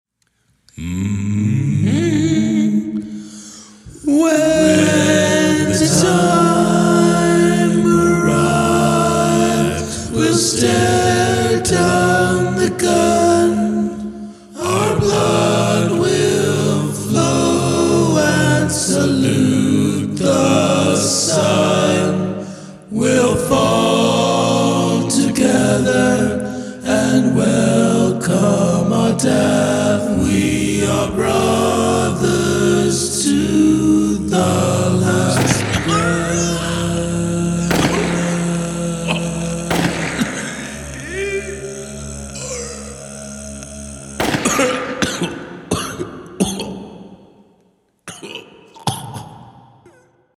Use of the sound of gunfire
It’s short, but perfect for an a capella tune.
I like how they drop off one at a time.